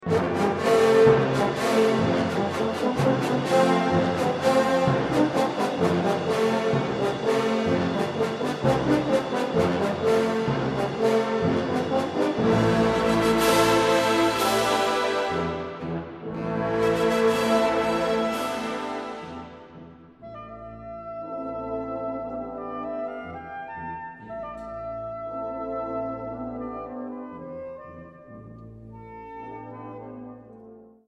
Op deze pagina kunt u verschillende korte fragmenten van de fanfare beluisteren van uitvoeringen op concoursen tussen 1993 en 2007.
Uitvoering: Wereld Muziek Concours 1993